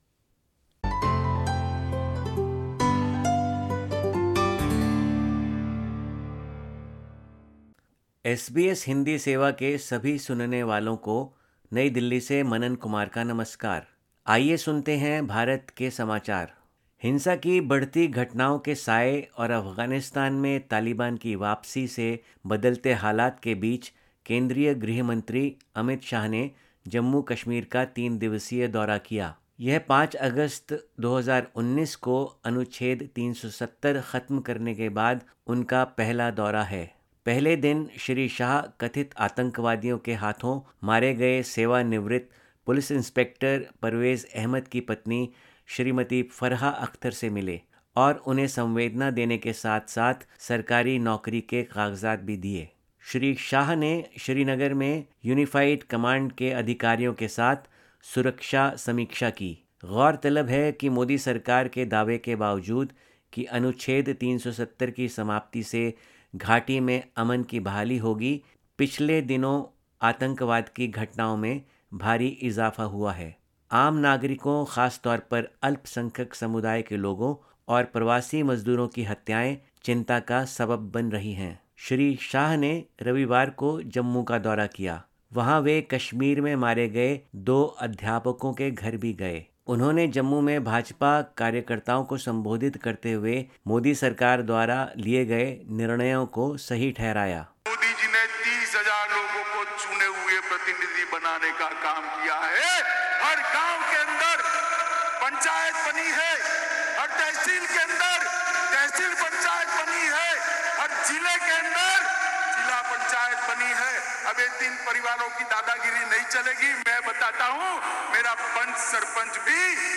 भारत के समाचार हिन्दी में